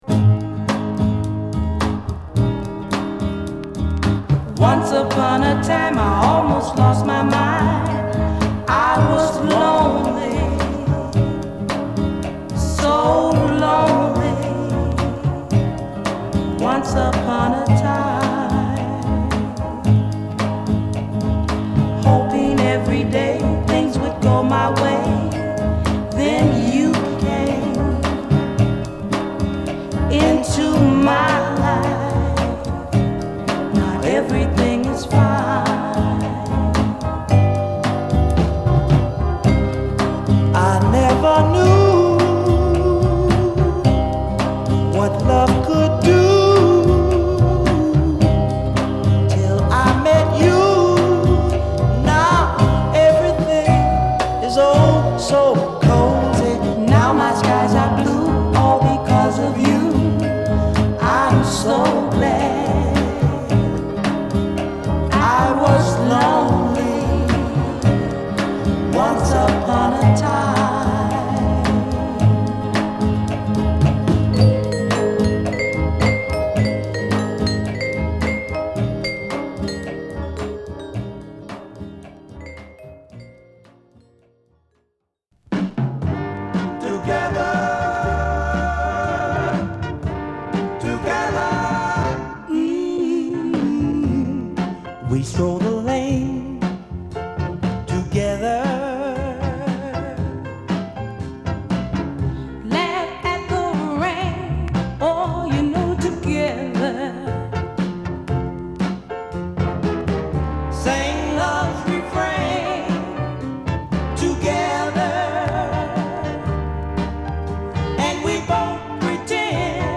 息のあったデュエットを披露！！